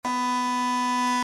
In 2010, this plastic horn infamously made